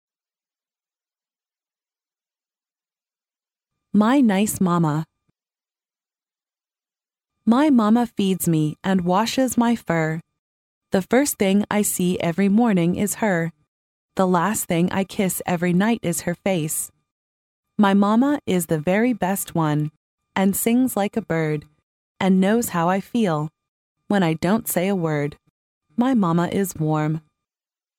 幼儿英语童谣朗读 第10期:我的好妈妈 听力文件下载—在线英语听力室